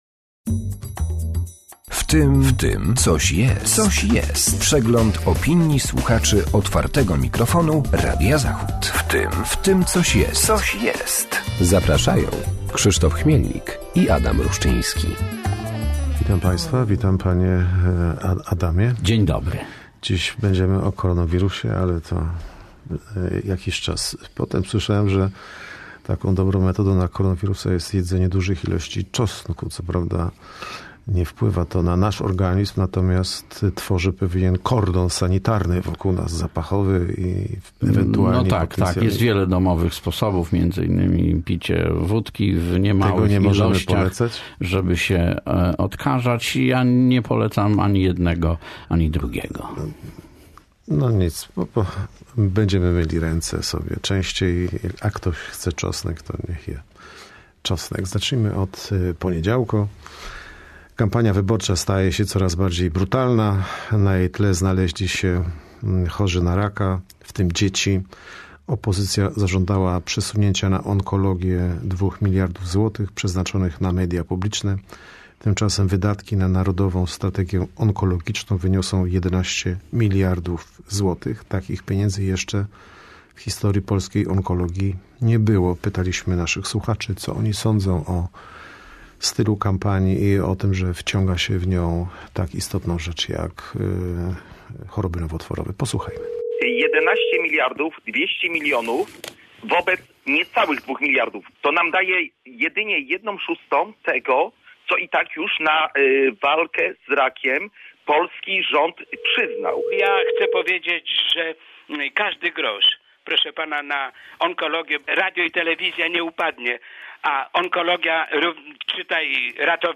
W cotygodniowej audycji przypominamy głosy słuchaczy Otwartego Mikrofonu oraz komentujemy tematy z mijającego tygodnia.